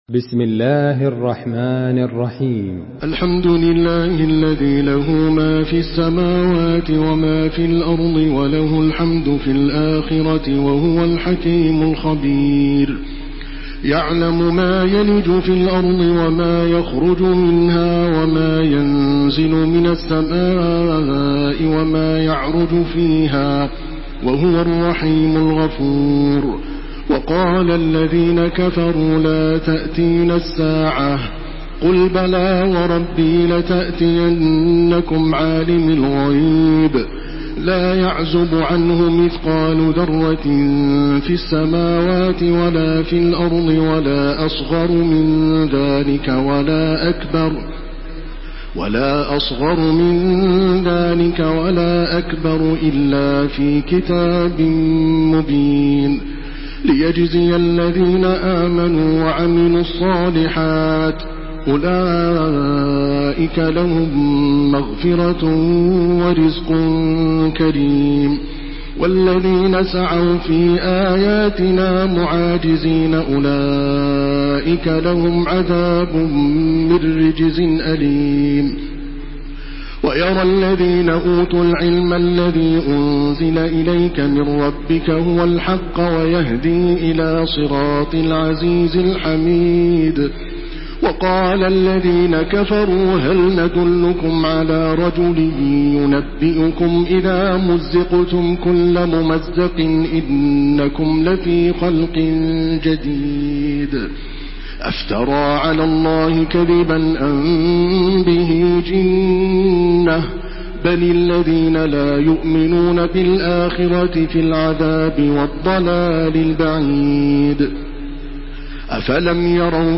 Surah Saba MP3 in the Voice of Makkah Taraweeh 1429 in Hafs Narration
Murattal